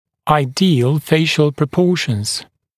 [aɪ’dɪəl ‘feɪʃl prə’pɔːʃnz][ай’диэл ‘фэйшл прэ’по:шнз]идеальные пропорции лица